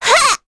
Miruru-Vox_Attack2.wav